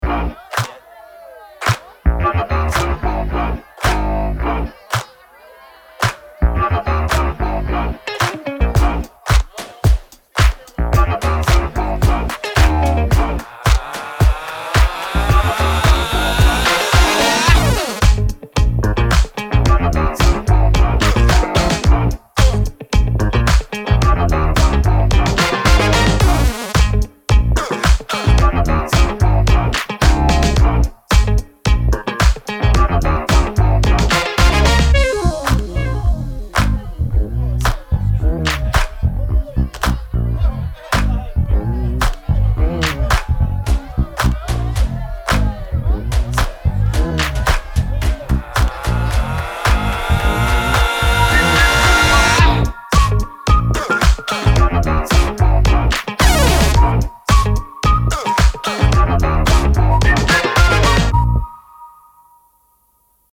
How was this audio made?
Dry Signal (No Effects) -1dB True Peak These are real-session settings, not extreme demonstrations.